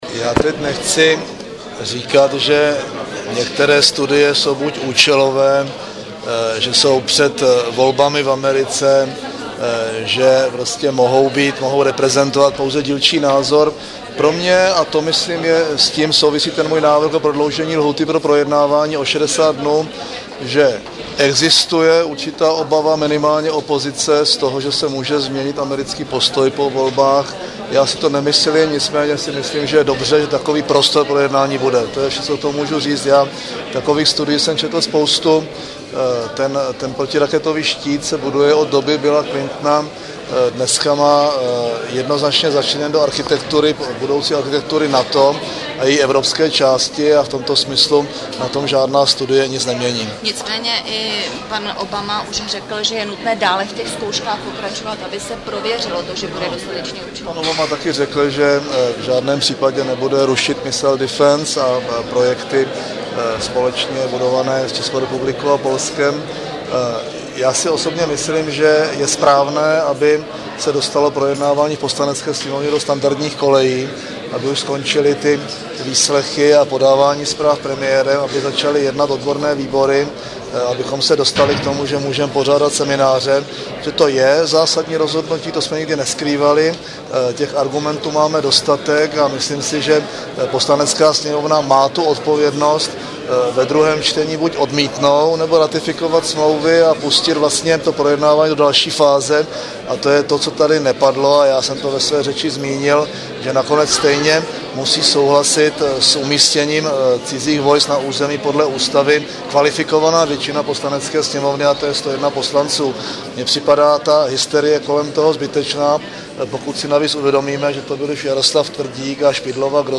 Předseda vlády Mirek Topolánek během dnešního jednání ve Sněmovně o radaru prohlásil k médiím, že volá po klidu a seriózní diskuzi, aby se dosáhlo výsledku v jednání této komory parlamentu.
Premiér odpovídal na dotazy novinářů k jednání Sněmovny o radaru